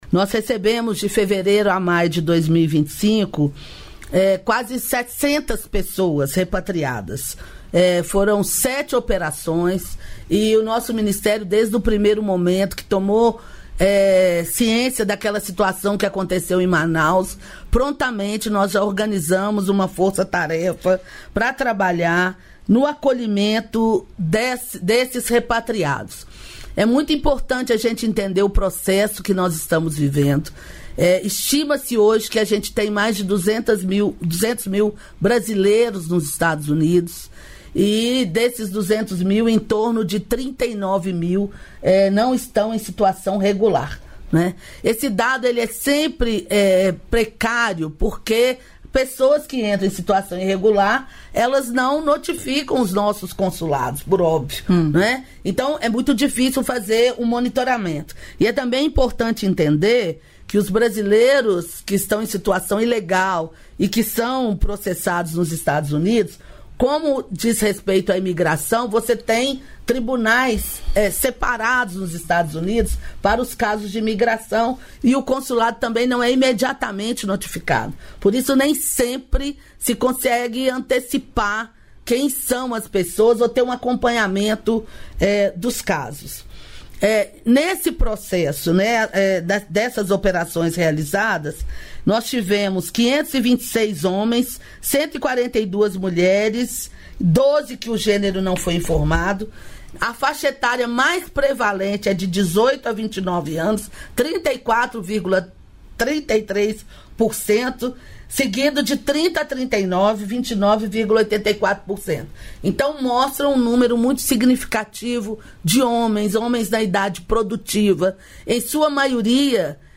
Trecho da participação da ministra dos Direitos Humanos e da Cidadania, Macaé Evaristo, no programa "Bom Dia, Ministra" desta quinta-feira (15), nos estúdios da EBC em Brasília (DF).